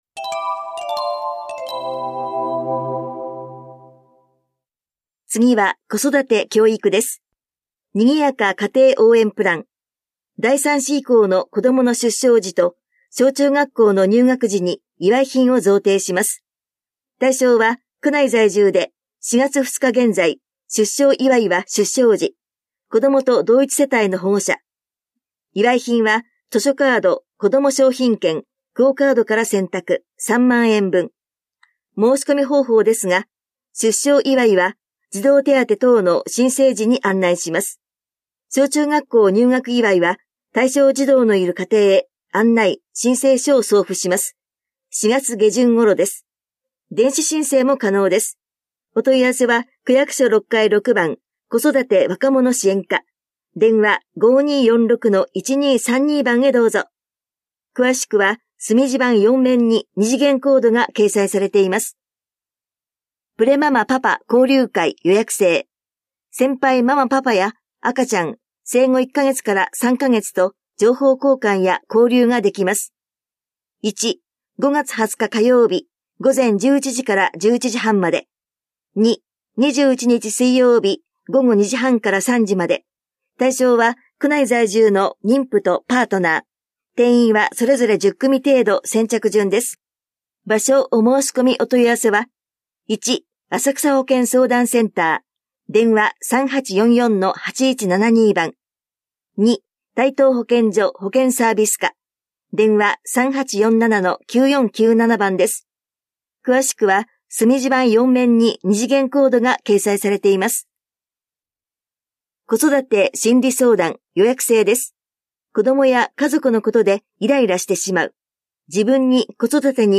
広報「たいとう」令和7年4月20日号の音声読み上げデータです。